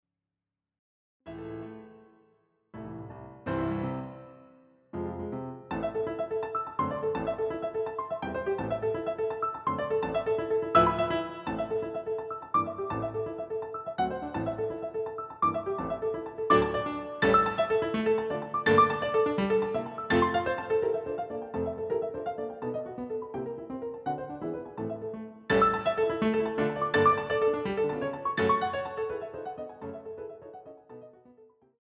using the stereo sampled sound of a Yamaha Grand Piano.